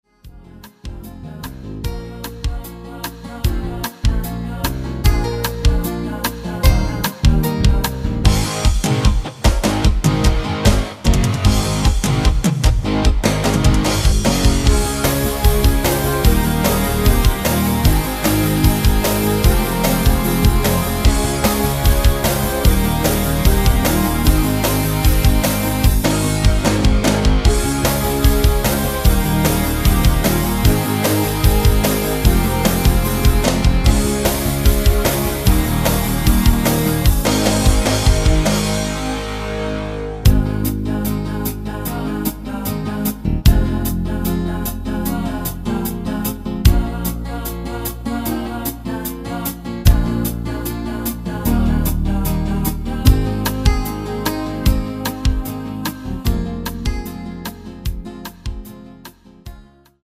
키 Ab 가수